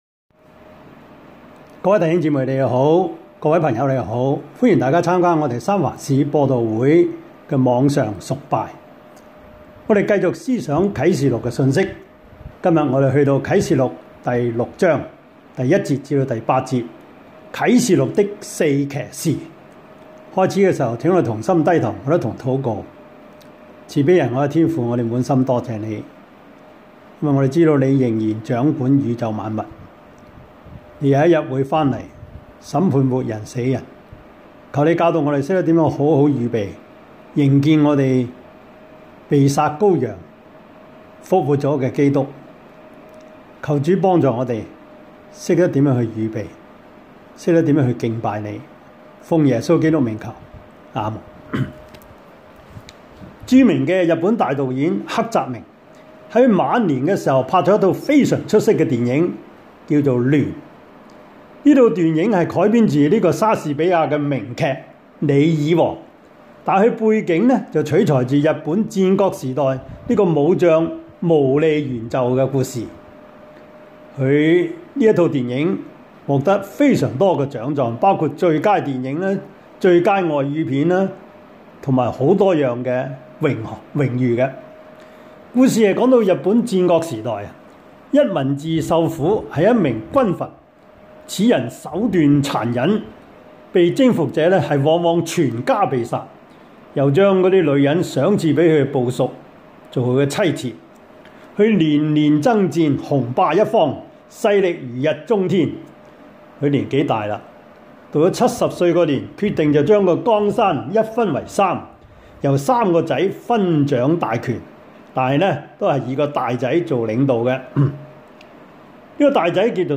Series: 2020 主日崇拜 |